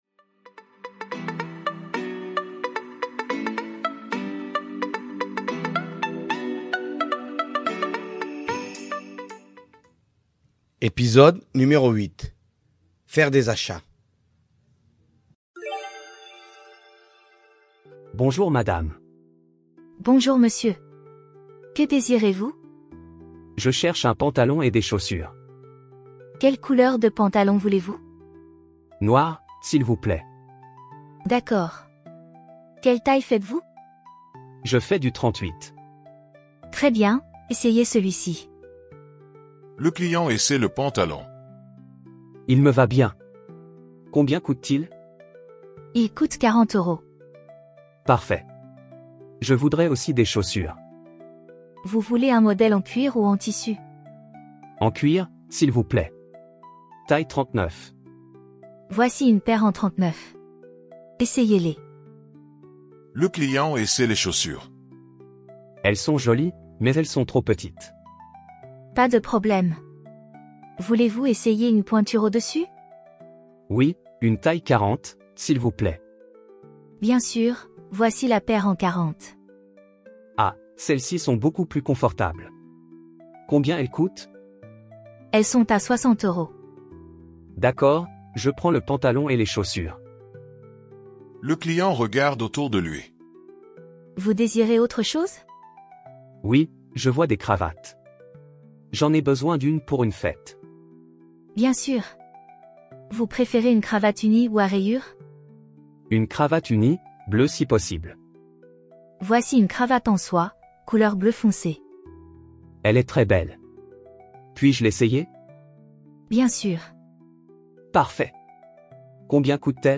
Voici un petit dialogue pour les débutants. Avec cet épisode, vous allez apprendre quelques expressions pour faire des achats.